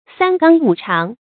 注音：ㄙㄢ ㄍㄤ ㄨˇ ㄔㄤˊ
三綱五常的讀法